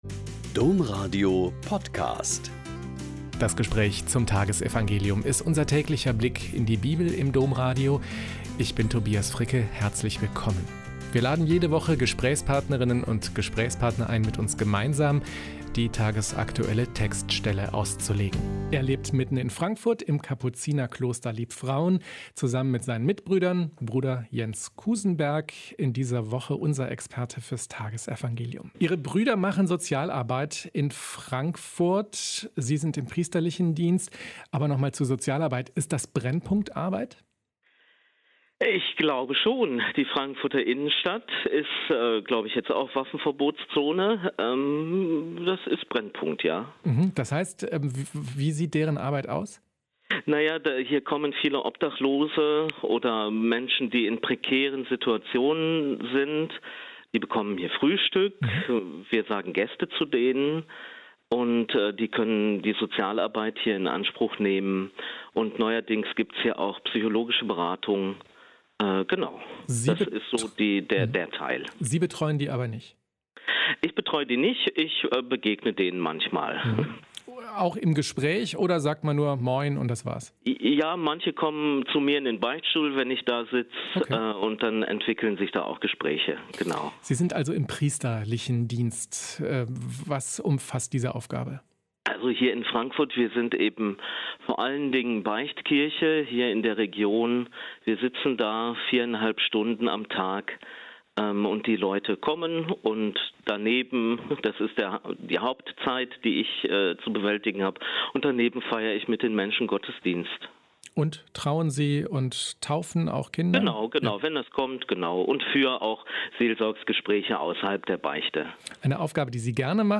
Lk 10,25-37 - Gespräch